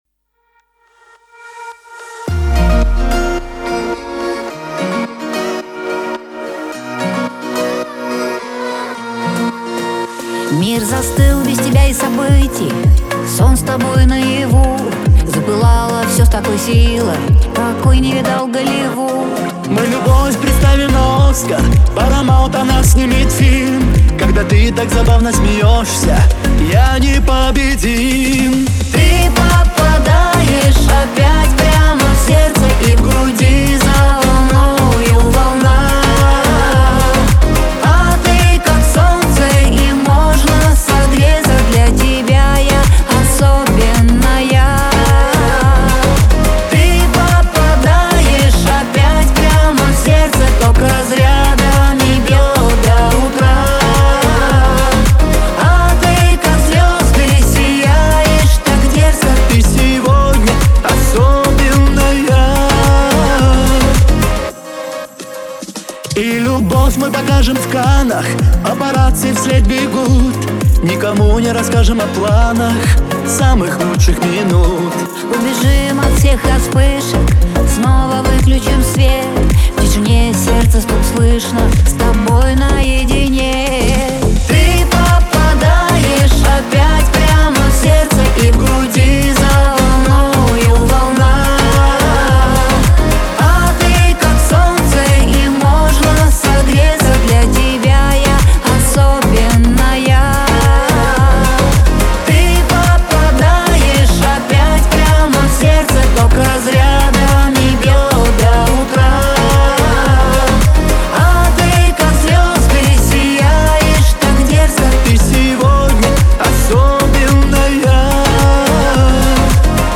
Энергичное и страстное признание в любви.